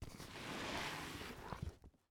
household
Duffle Bag Slide On Ground